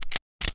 revolverempty.ogg